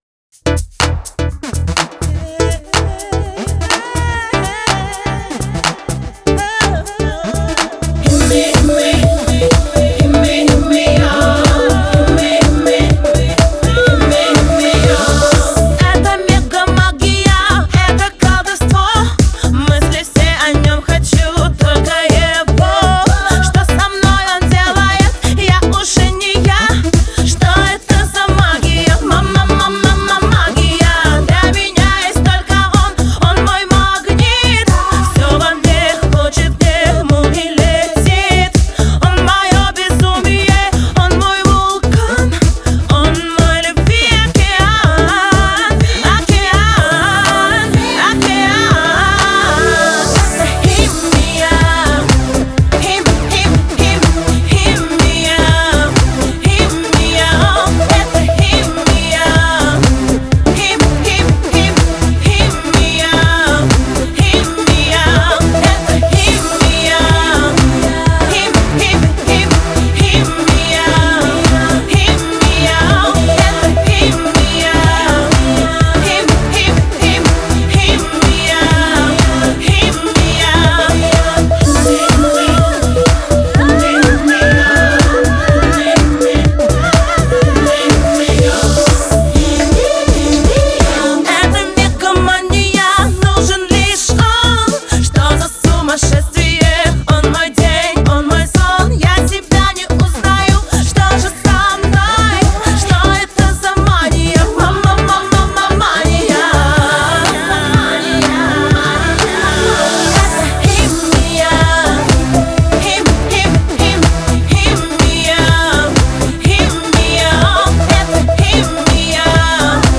Pop [52]